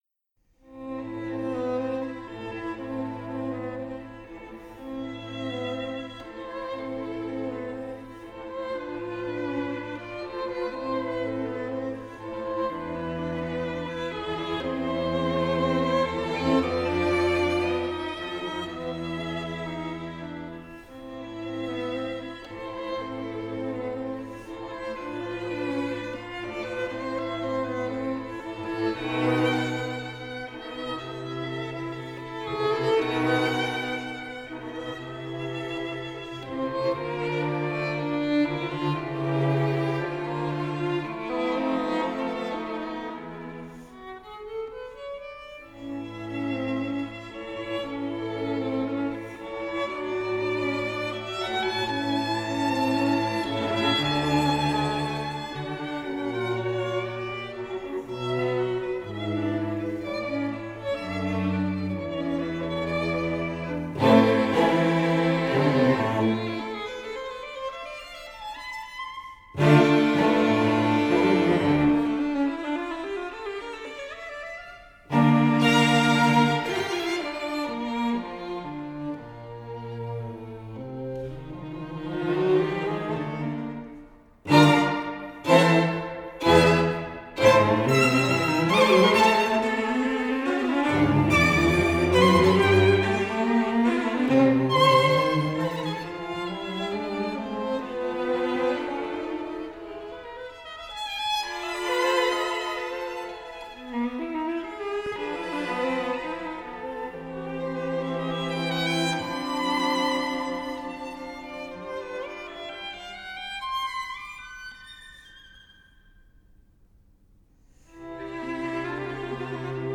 Franz Schubert: String Quartet D 804, op. 29 no 1 A minor "Rosamunde". I. Allegro ma non troppo.